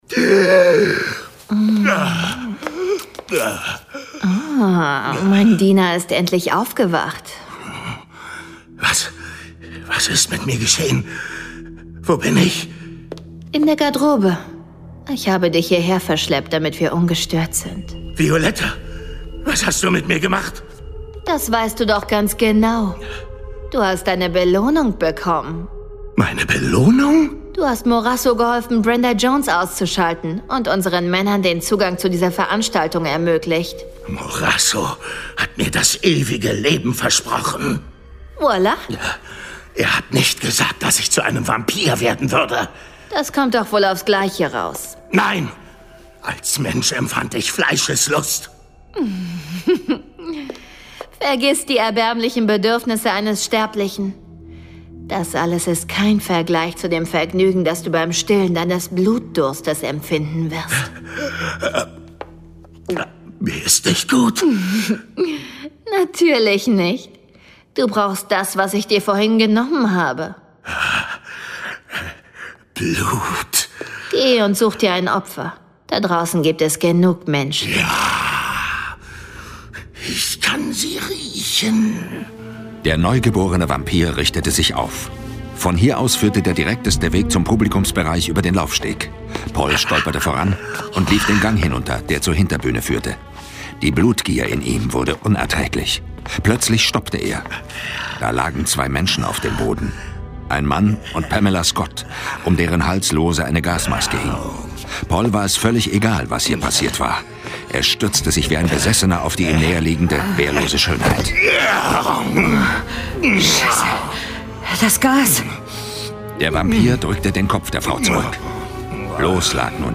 John Sinclair - Folge 52 Horrortrip zur Schönheitsfarm. Hörspiel.